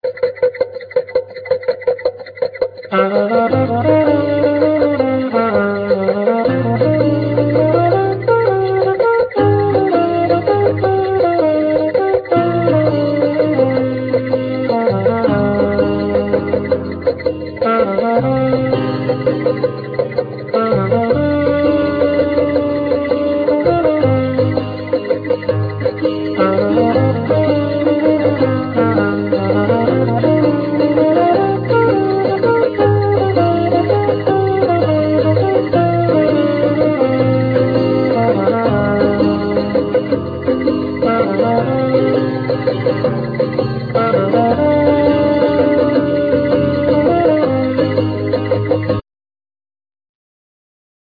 Ac.guitar,Organ,Piano,Bass,Melodica,Flute,Xylophne
Trumpet,Tronbone,Contrabass
Sanza,Steel-drum